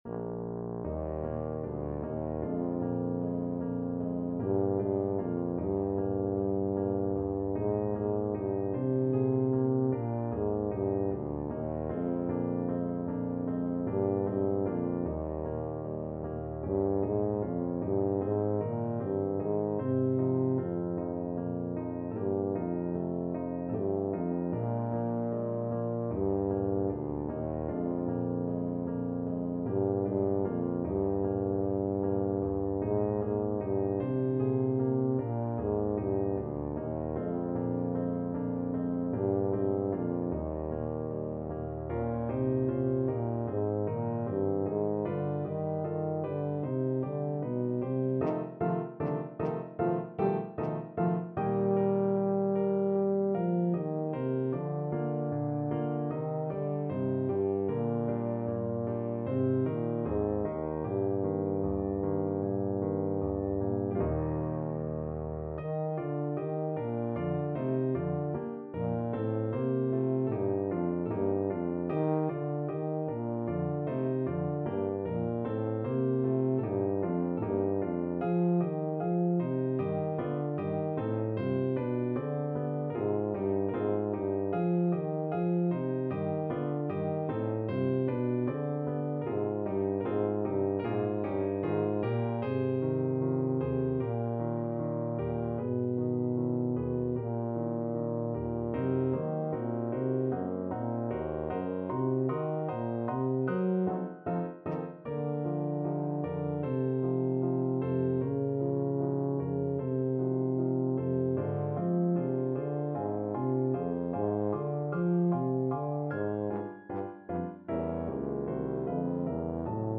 2/4 (View more 2/4 Music)
Ab2-G4
Lento ma non troppo = c.76
Classical (View more Classical Tuba Music)